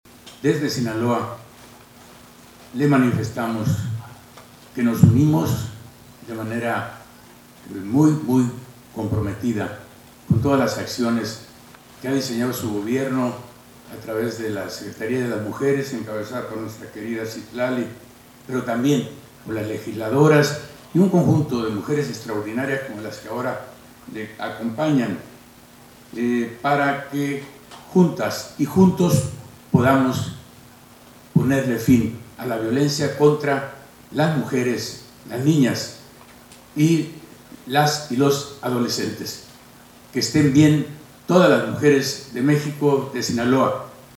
Culiacán, Sinaloa, a 25 de noviembre de 2025.- En el marco del Día para Erradicar la Violencia contra las Mujeres, el Gobernador Rubén Rocha Moya participó en un enlace nacional a la Conferencia Mañanera de la Presidenta de la República, Claudia Sheinbaum Pardo, a través del cual los 32 mandatarios estatales se adhirieron al “Compromiso Nacional por la Vida, la Felicidad y el Respeto a las Mujeres”, iniciativa del Gobierno Federal que busca combatir la violencia contra el género femenino y promover su bienestar.